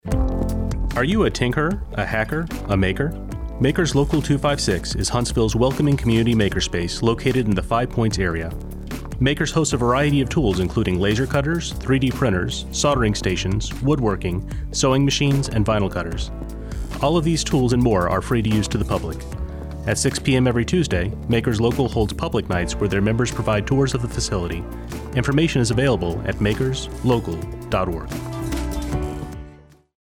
Makers Local 256 is thrilled to have a PSA being played via local NPR station WLRH.
MakersLocal256PSA-from-WLRH.mp3